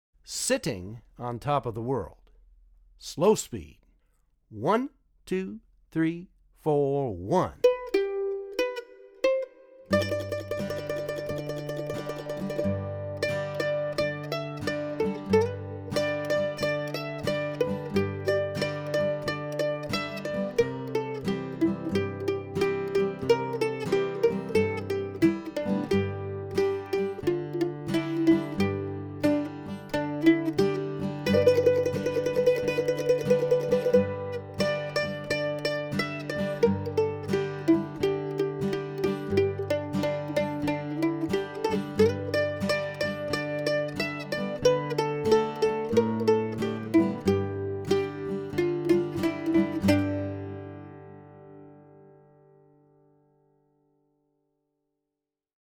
DIGITAL SHEET MUSIC - MANDOLIN SOLO
Traditional Mandolin Solo
Online Audio (both slow and regular speed)